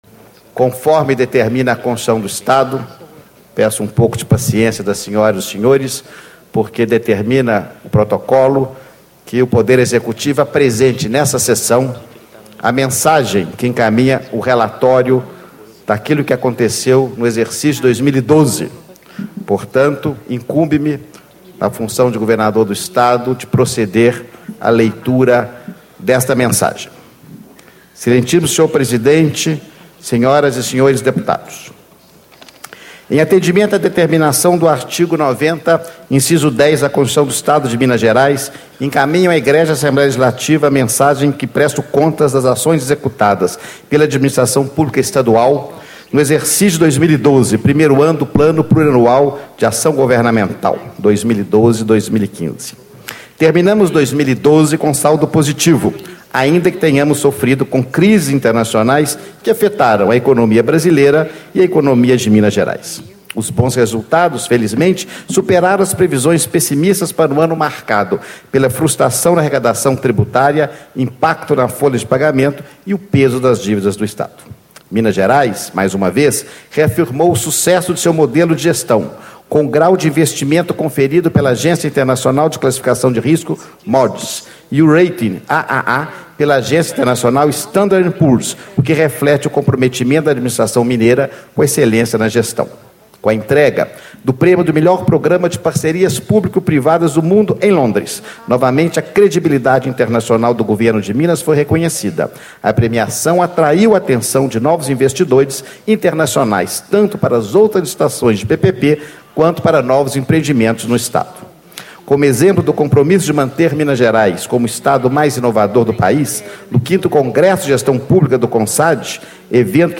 Antonio Augusto Anastasia, Governador do Estado de Minas Gerais
Reunião Solene para Instalação da 3ª Sessão Legislativa da 17ª Legislatura